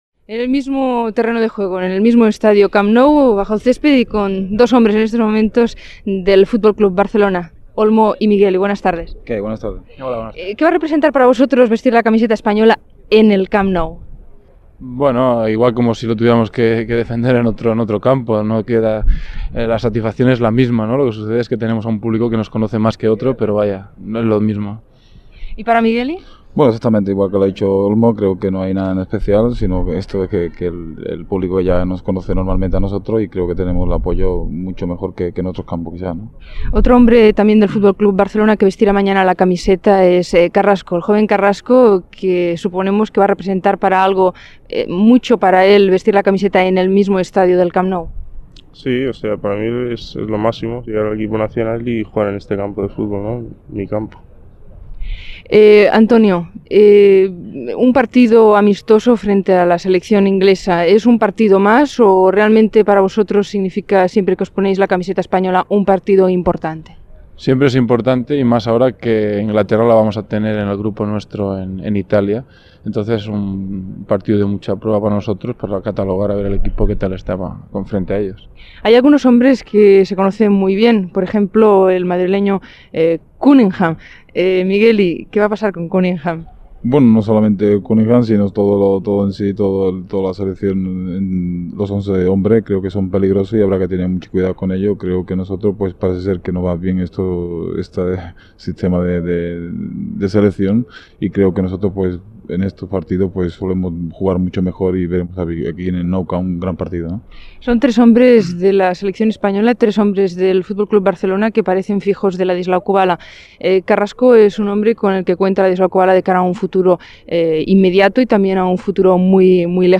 Àudio: arxiu sonor Ràdio Barcelona
Esports: entrevista jugadors del FCB - Radio Barcelona, 1980